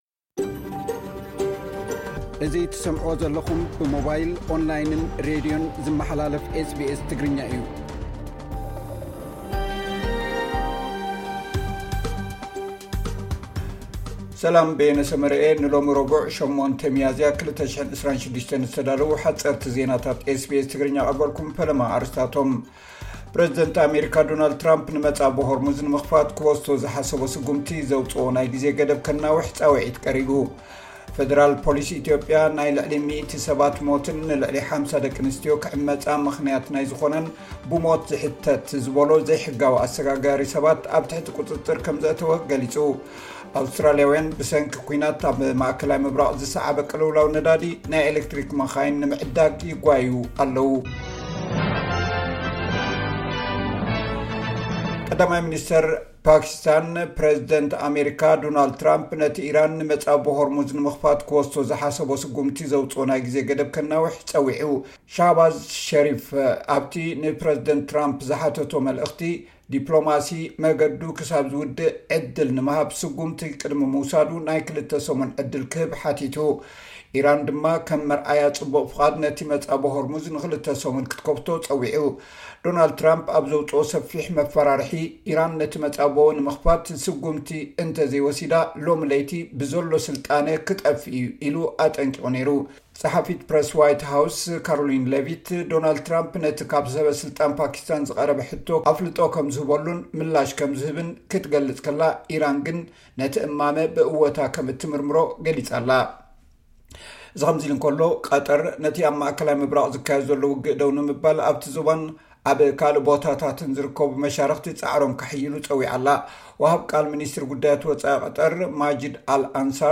SBS Tigrinya Newsflash